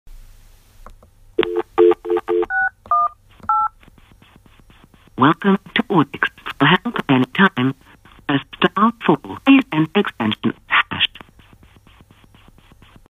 When making calls the phone appears to make a pulsing noise from its speaker when off hook and we're not sure why?
I've attached an MP3 showing the pulsing noise when off hook and dialling our voicemail system as a test.
test dial.mp3